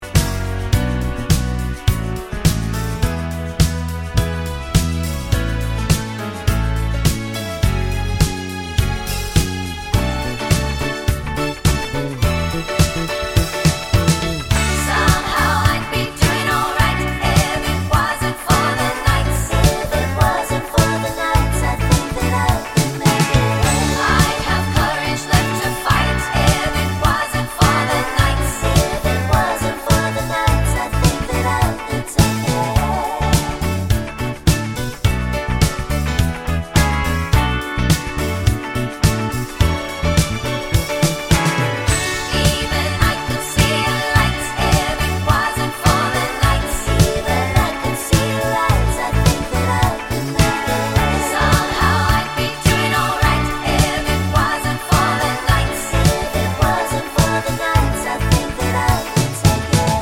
Duet Version Pop (1970s) 4:38 Buy £1.50